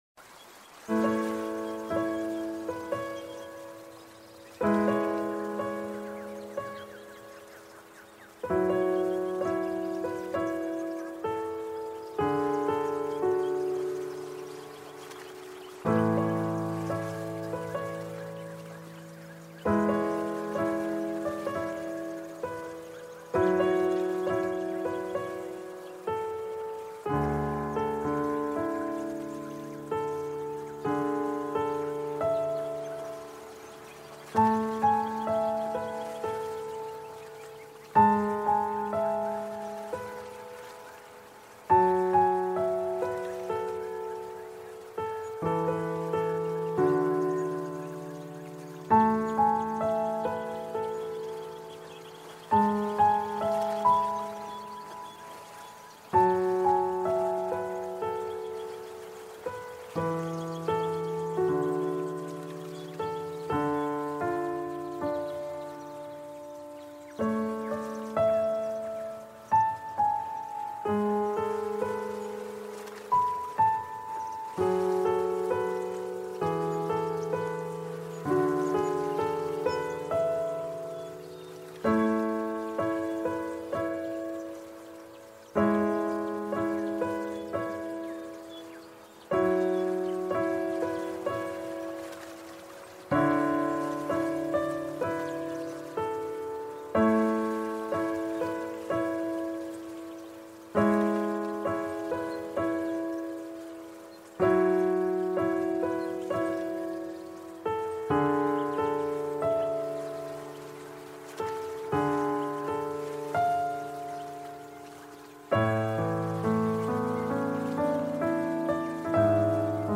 Tokyo LoFi 90s : Ambiance Pour Dormir